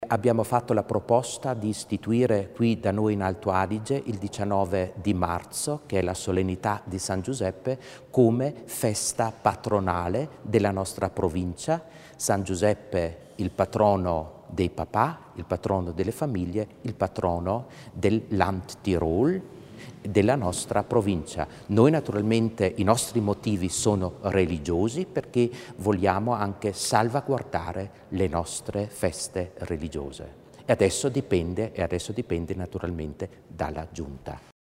Il Vescovo Muser spiega i progetti di collaborazione con la Giunta provinciale